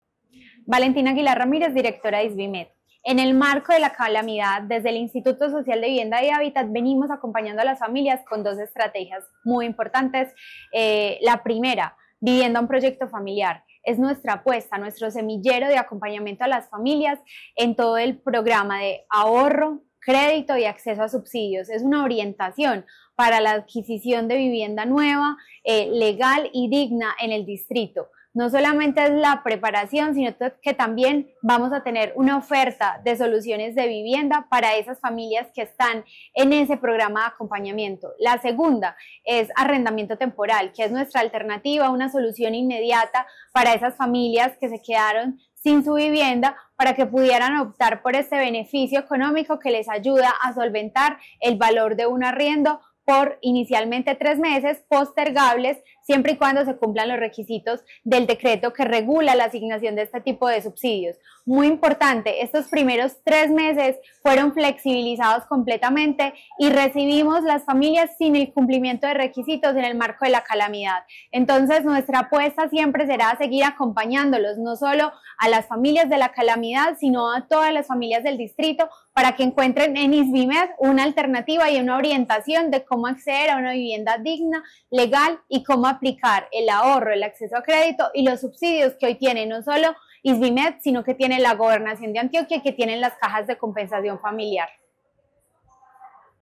Declaraciones directora del Isvimed, Valentina Aguilar Ramírez.
Declaraciones-directora-del-Isvimed-Valentina-Aguilar-Ramirez.-Familias-afectadas-por-lluvias.mp3